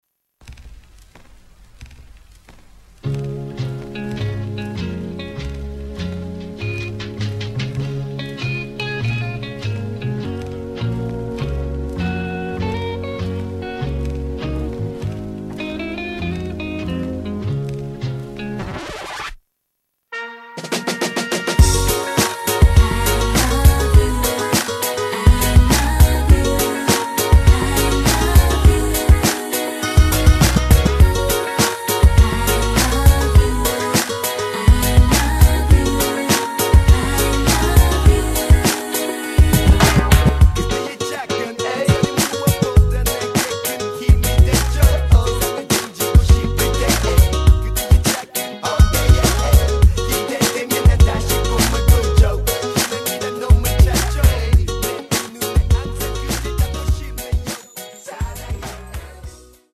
음정 원키
장르 가요 구분